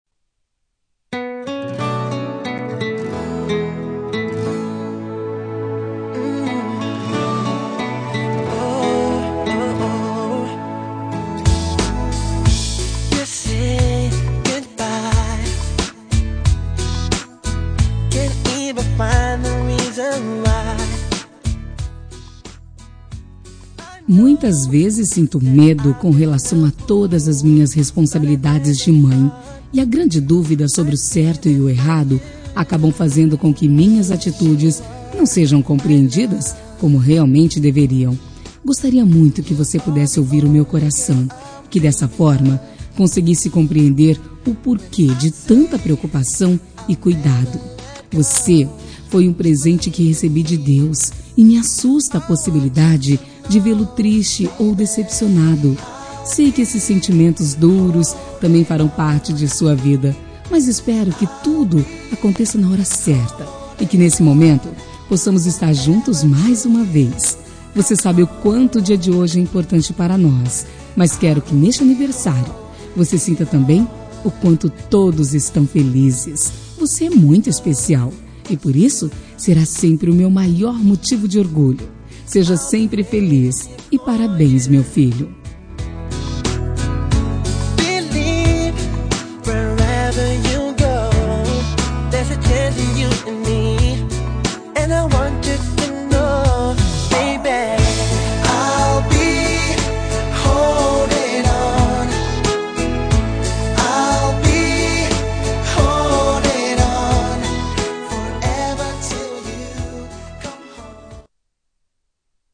Telemensagem de Aniversário de Filho – Voz Feminina – Cód: 1839